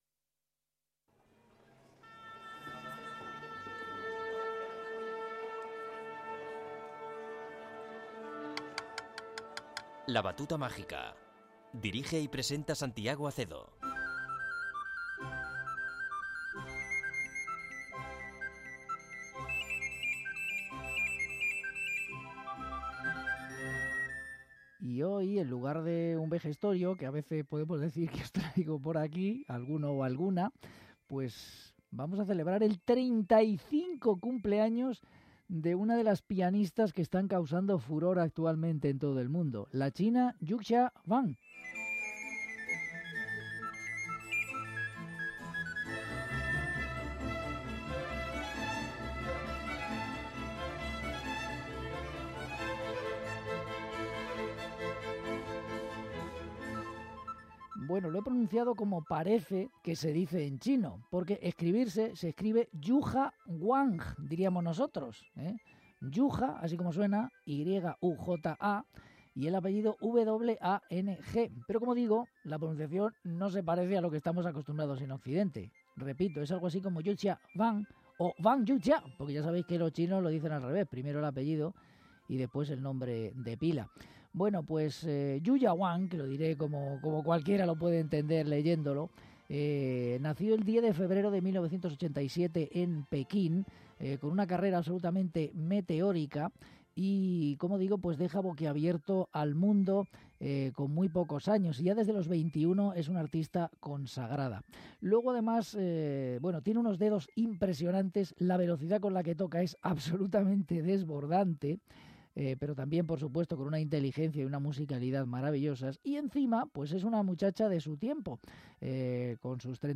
Transcripción para Piano